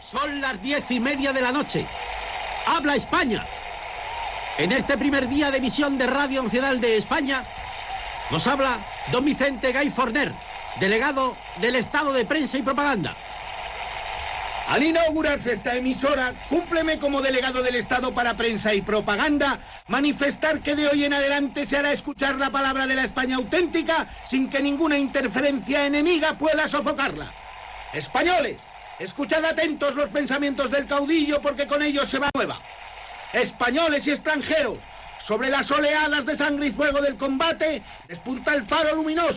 Recreació de l'emissió inaugural feta pel locutor Fernando Fernández de Córdoba i pel cap de la Delegación de Prensa i Propaganda Vicente Gay Forner des del Palacio de Anaya de Salamanca (Les veus no es corresponen amb les de les persones esmentades).